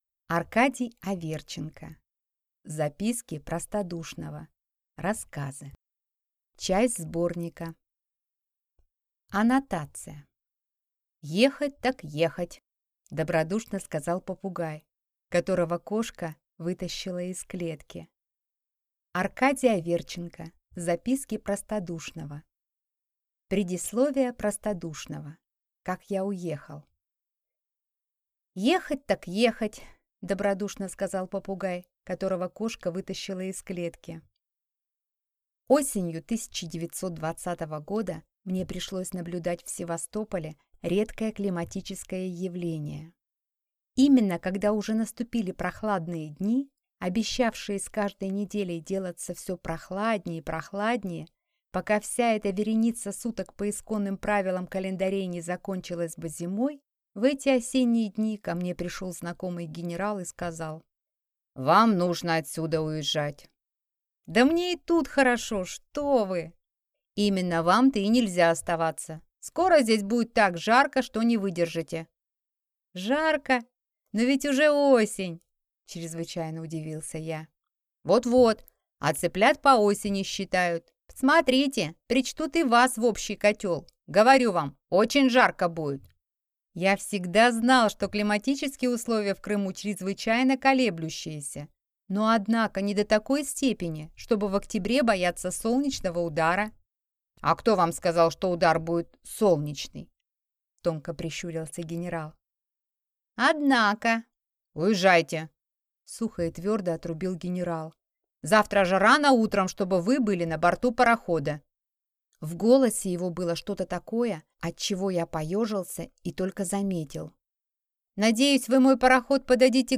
Аудиокнига Записки простодушного | Библиотека аудиокниг